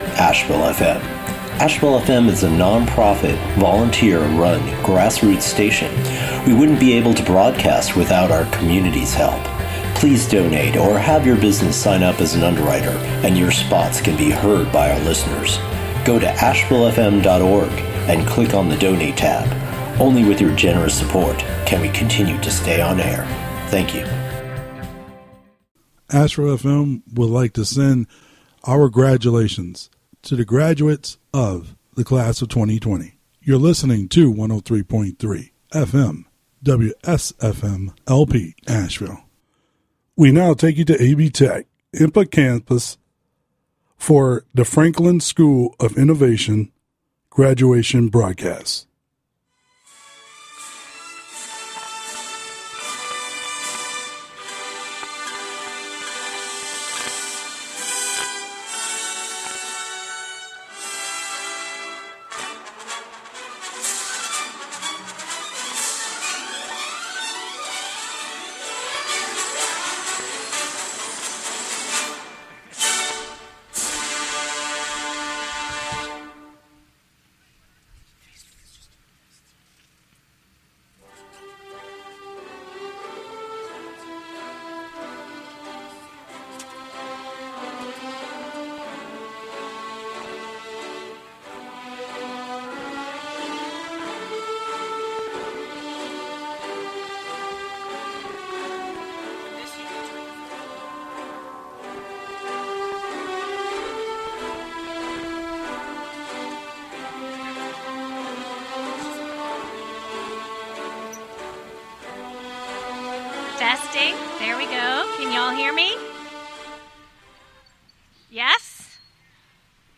Franklin School of Innovation – Class of 2020 – Graduation Ceremony – Recorded at the AB Tech-Enka campus parking lot.